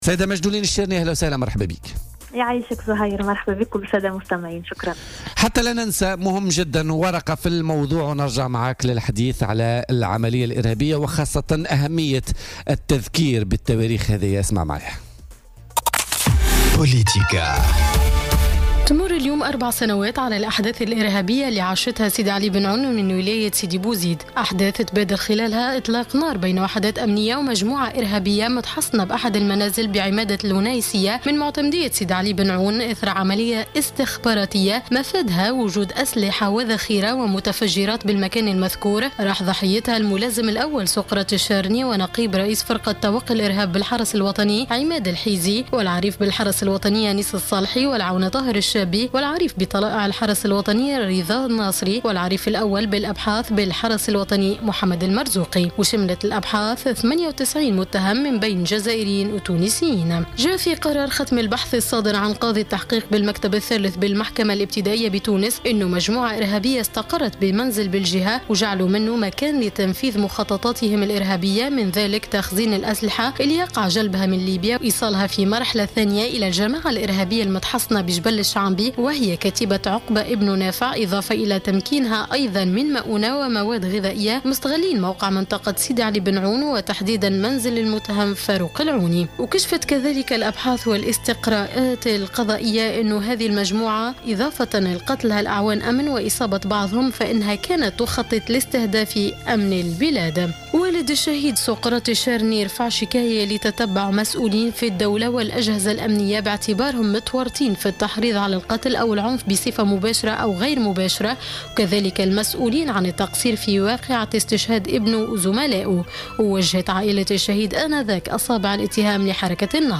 قالت شقيقة الشهيد سقراط الشارني ووزيرة الشباب والرياضة ماجدولين الشارني خلال مداخلتها في برنامج "بوليتيكا"، إنه انتظم اليوم الاثنين بمدينة الكاف موكب لإحياء الذكرى الرابعة لاستشهاد الرائد بالحرس الوطني سقراط الشارني.